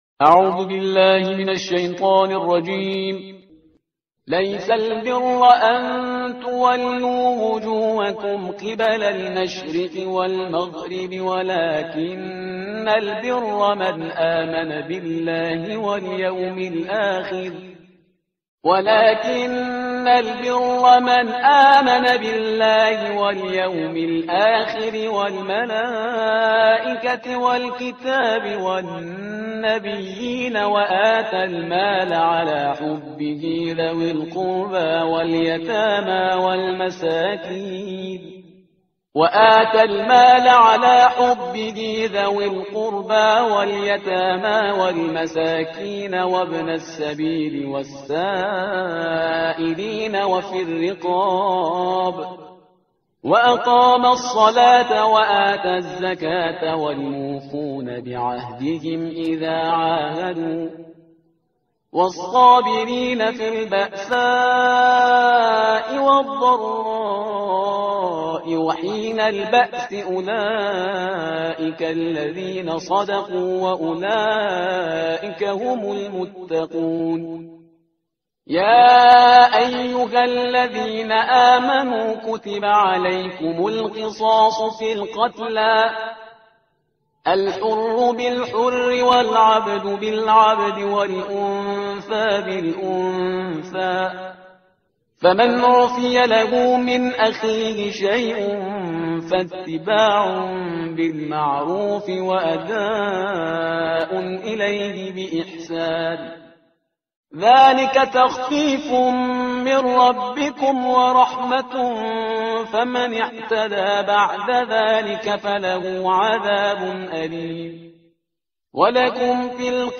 ترتیل صفحه 27 قرآن با صدای شهریار پرهیزگار